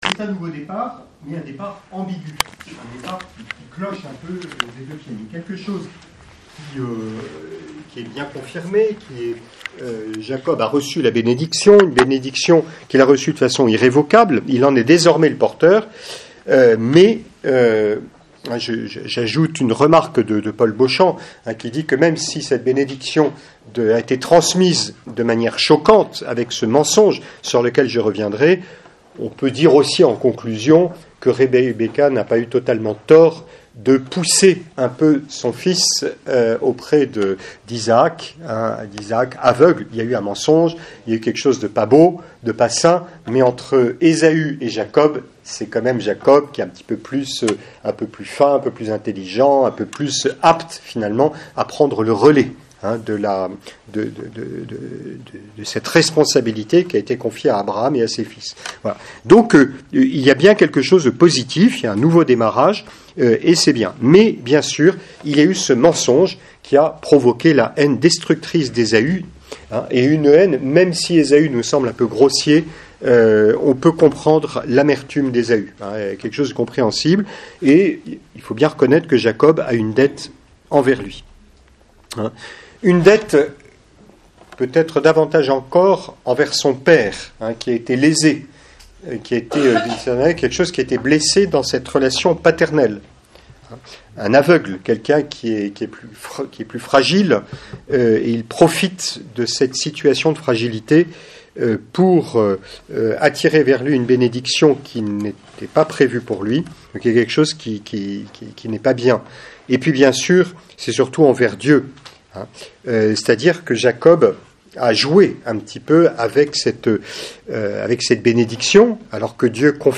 6e-cours.mp3